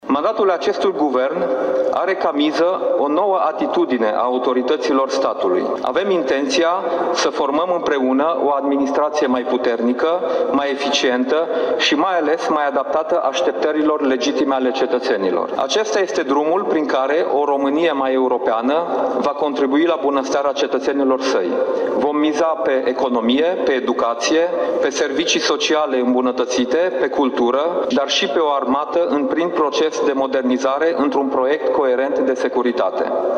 Şedinţa solemnă consacrată Zilei Naţionale este în plină desfăşurare în plenul Senatului, în prezenţa premierului Dacian Cioloş şi a unor miniştri din Cabinetul său.
În discursul său, prim-ministrul Dacian Cioloş, a insistat pe ideea de unitate şi stabilitate instituţională.